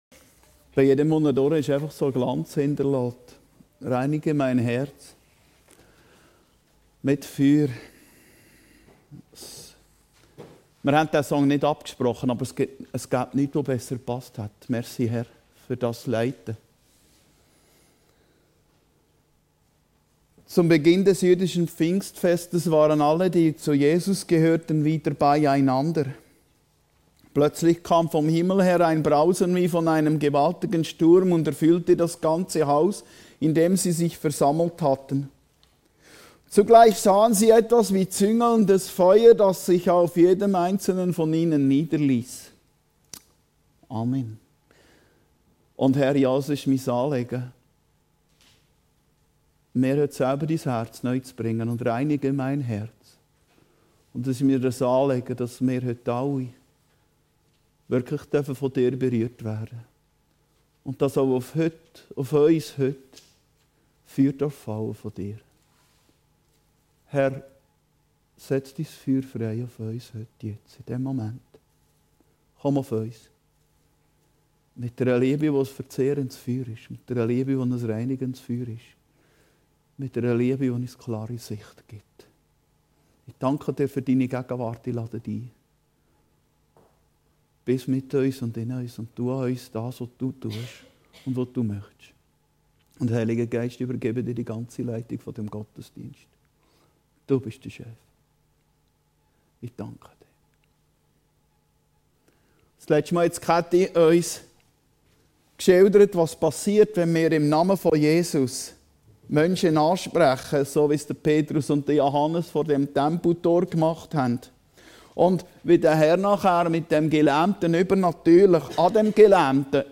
Predigten Heilsarmee Aargau Süd – Ein Tempel des Heiligen Geistes_